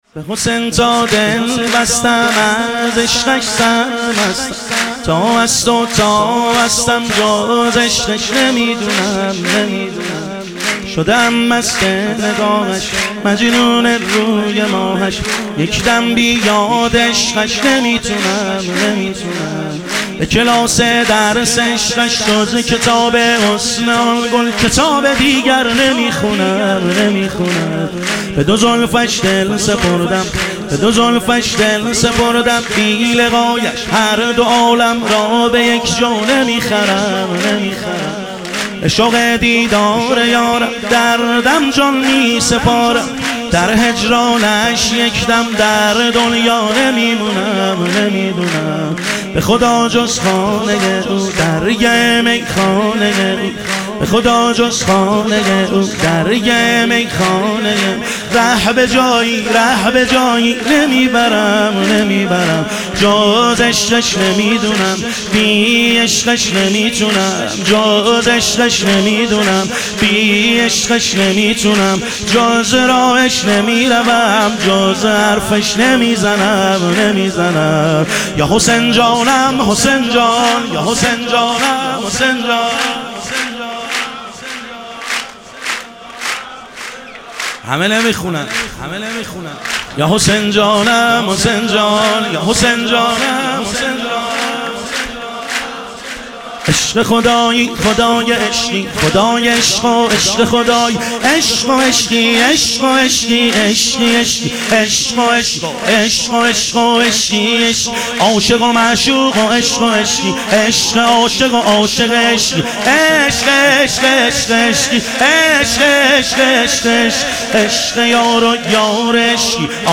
ظهور وجود مقدس امام حسین علیه السلام - تک